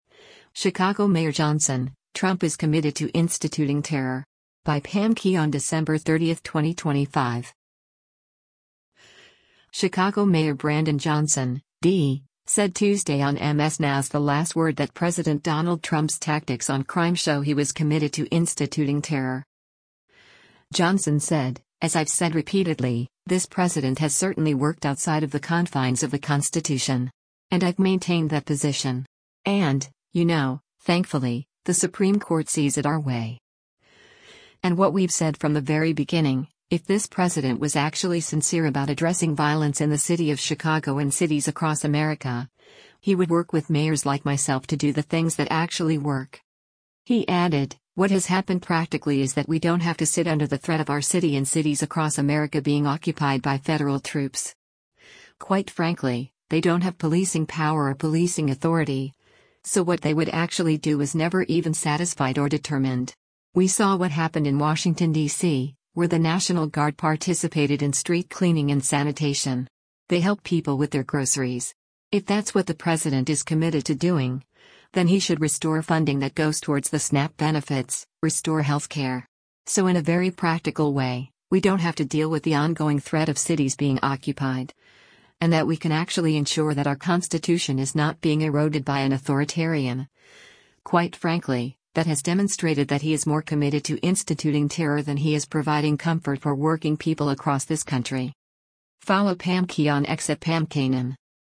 Chicago Mayor Brandon Johnson (D) said Tuesday on MS NOW’s “The Last Word” that President Donald Trump’s tactics on crime show he was “committed to instituting terror.”